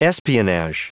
Prononciation du mot espionage en anglais (fichier audio)
Prononciation du mot : espionage